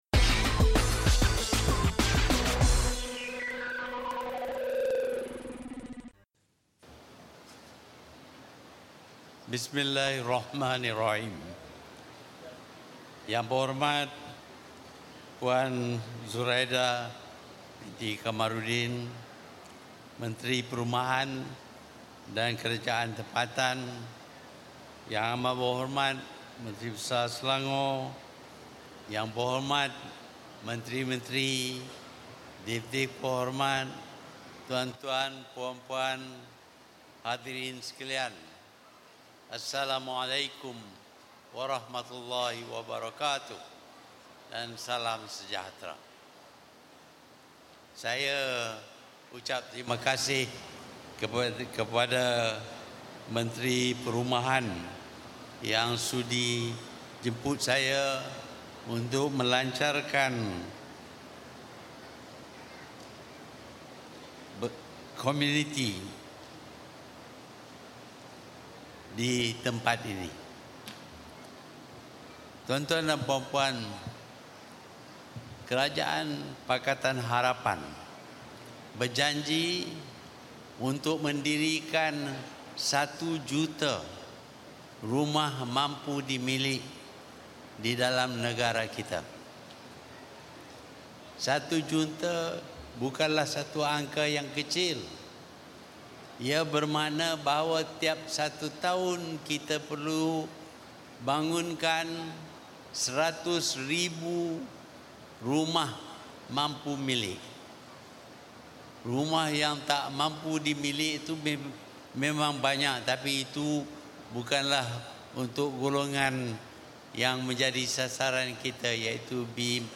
Ikuti ucapan penuh Perdana Menteri Tun Dr Mahathir Mohamad ketika melancarkan Dasar Komuniti Negara di Padang Awam, Taman Puchong Indah, Puchong.